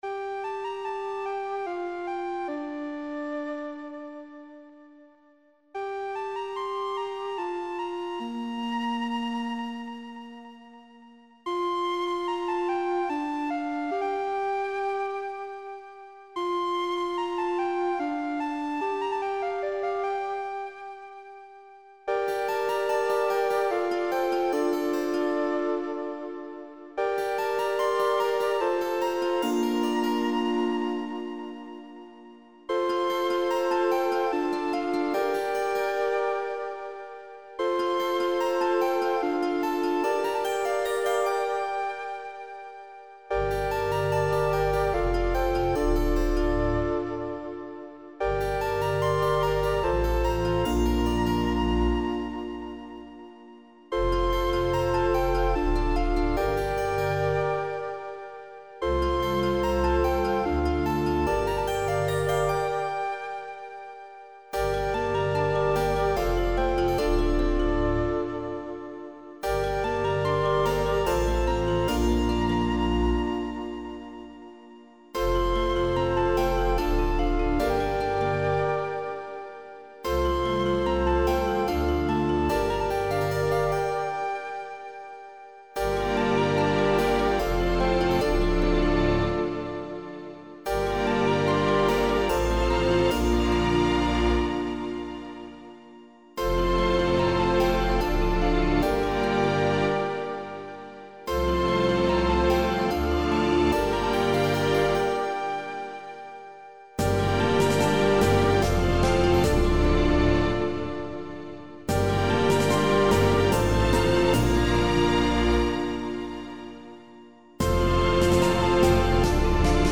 2:49 – New Medieval / Ambient-Symphonic.